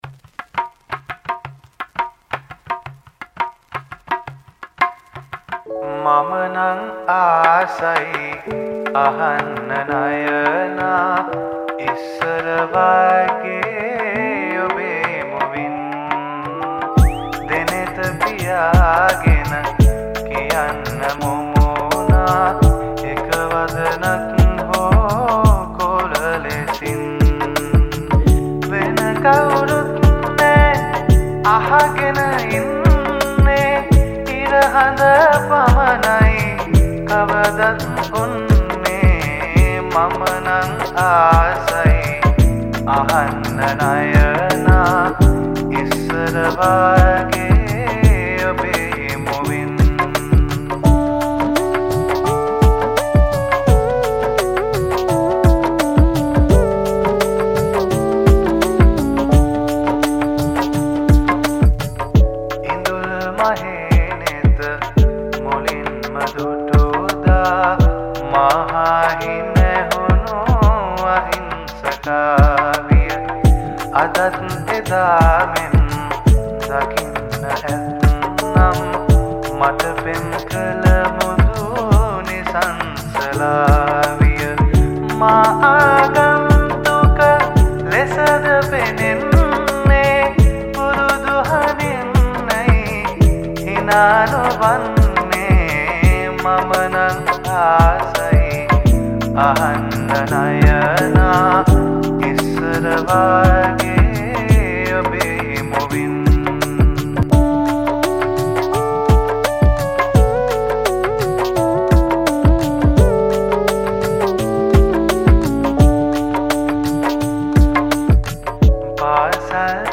Cover Vocals